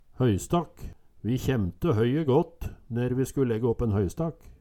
DIALEKTORD